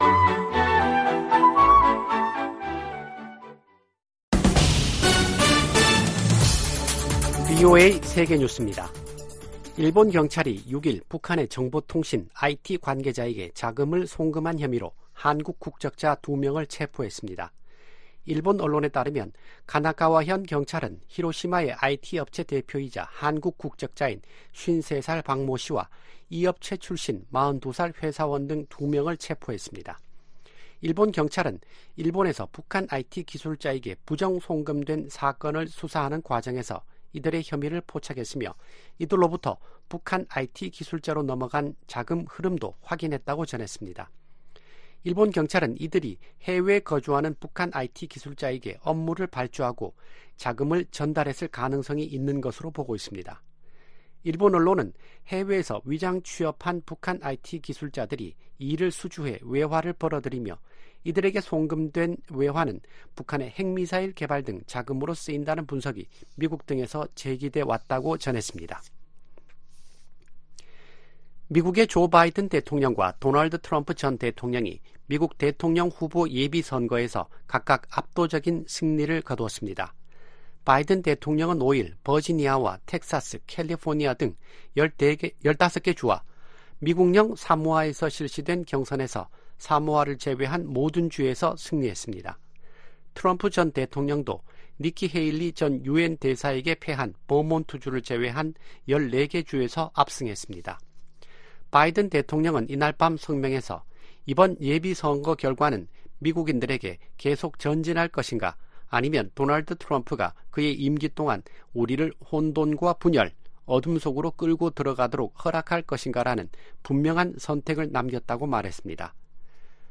VOA 한국어 아침 뉴스 프로그램 '워싱턴 뉴스 광장' 2024년 3월 7일 방송입니다. 미 국무부 고위 관리가 북한 비핵화에 중간 단계 조치 필요성을 인정했습니다.